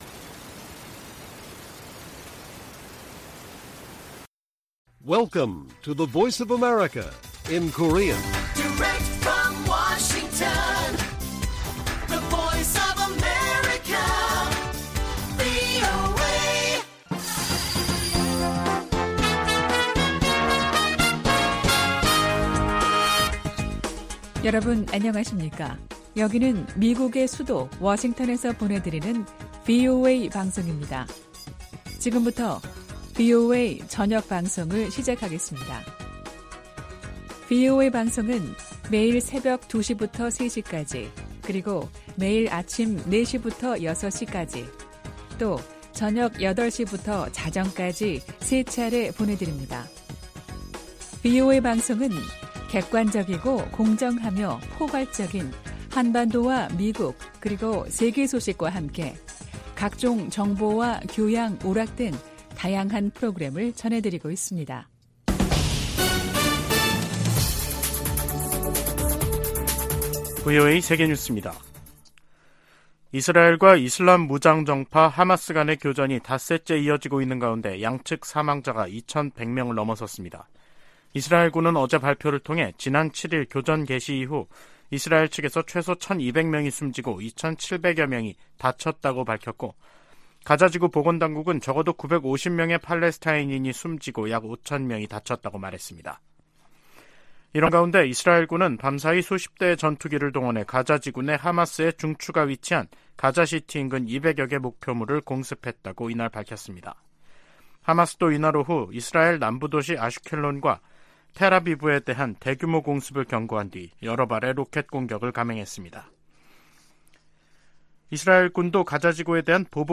VOA 한국어 간판 뉴스 프로그램 '뉴스 투데이', 2023년 10월 11일 1부 방송입니다. 조 바이든 미국 대통령이 이스라엘에 대한 하마스의 공격을 테러로 규정하고 이스라엘에 전폭적 지원을 약속했습니다. 한국 군 당국이 하마스의 이스라엘 공격 방식과 유사한 북한의 대남 공격 가능성에 대비하고 있다고 밝혔습니다. 미 국무부가 하마스와 북한 간 무기 거래 가능성과 관련해 어떤 나라도 하마스를 지원해선 안 된다고 강조했습니다.